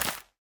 Minecraft Version Minecraft Version 1.21.5 Latest Release | Latest Snapshot 1.21.5 / assets / minecraft / sounds / block / bamboo / sapling_place3.ogg Compare With Compare With Latest Release | Latest Snapshot
sapling_place3.ogg